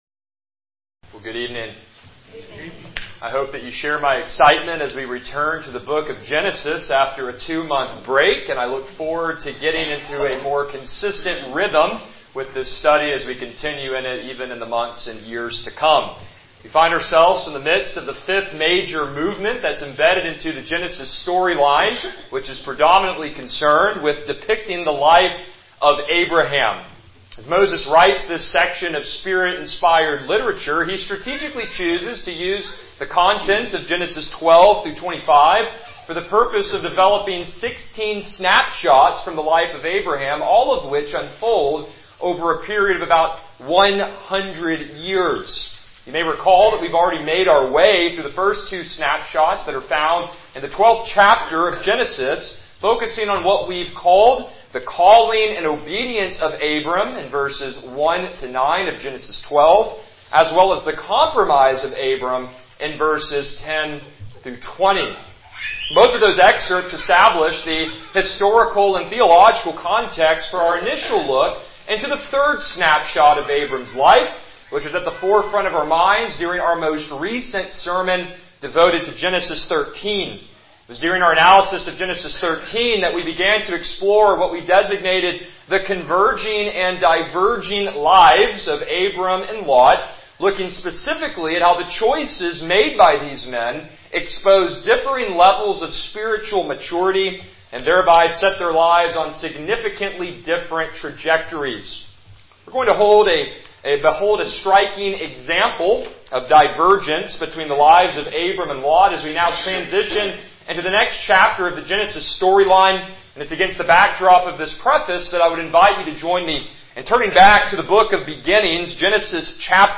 Passage: Genesis 14:1-16 Service Type: Evening Worship